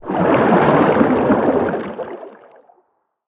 Sfx_creature_jellyfish_swim_os_01.ogg